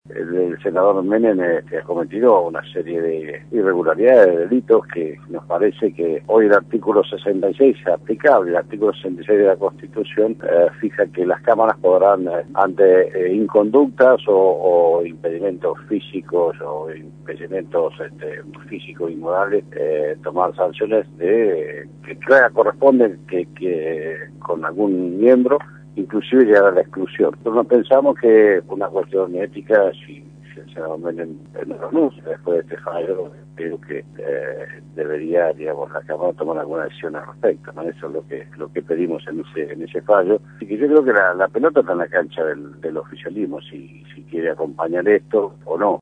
Jaime Linares, diputado nacional, por Radio La Red